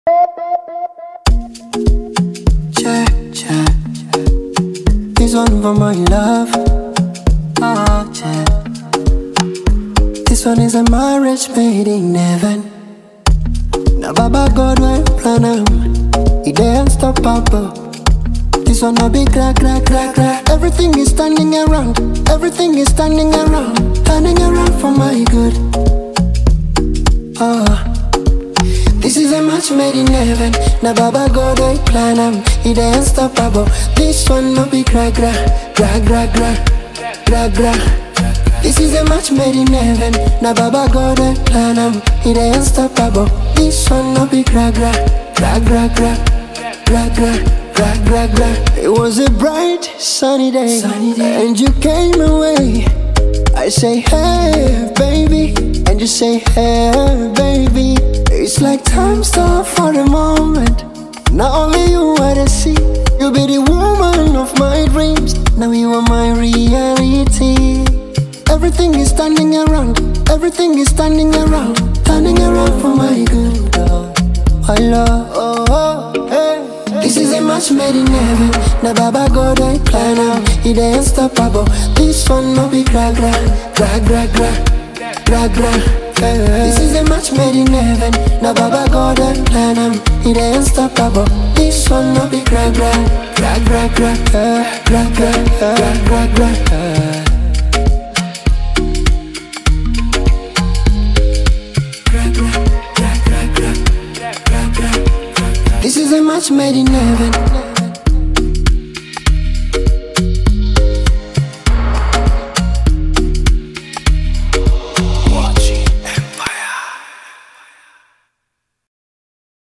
soulful Gospel ballad
Gospel-Romance sub-genre